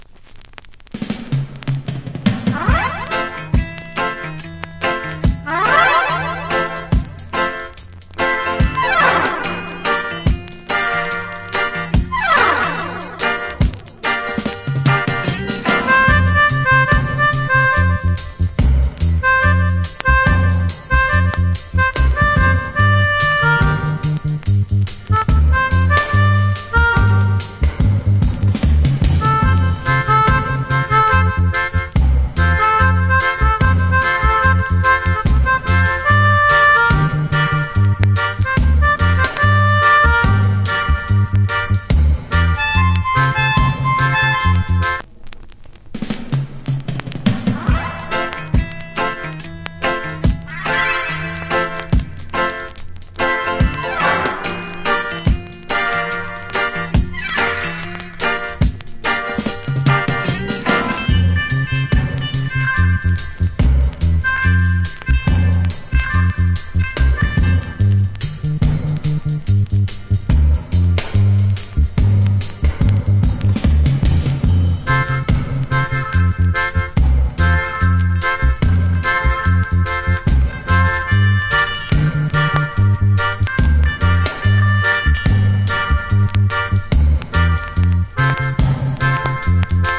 Good Melodica Reggae Inst.